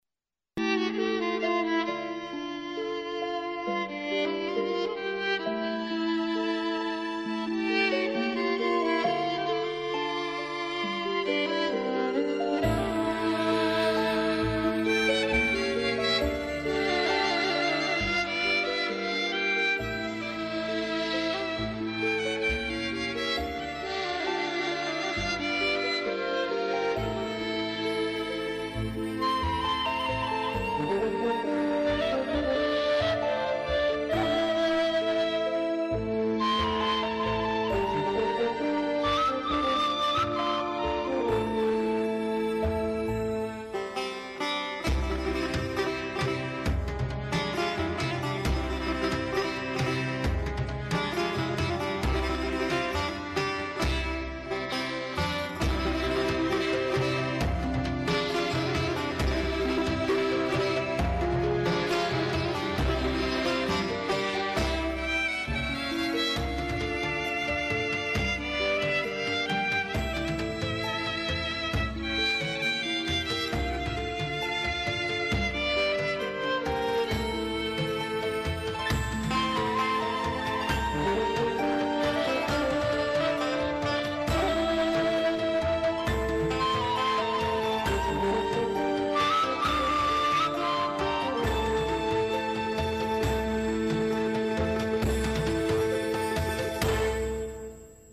آهنگ تیتراژ با صدای